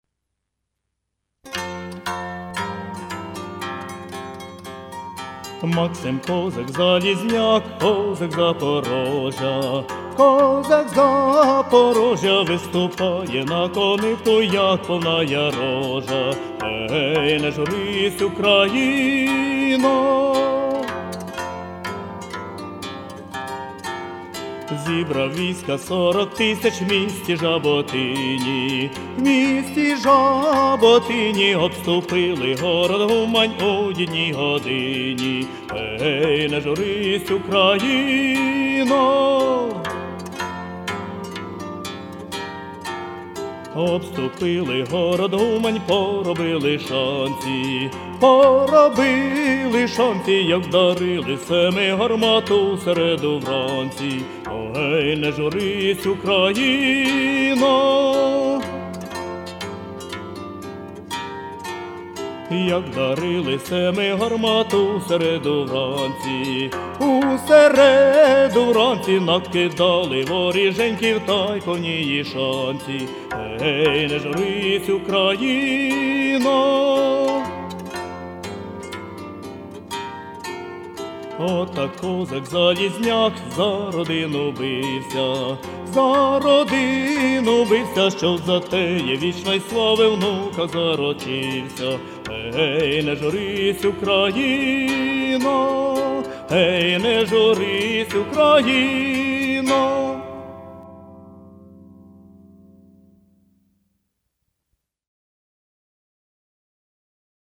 історична пісня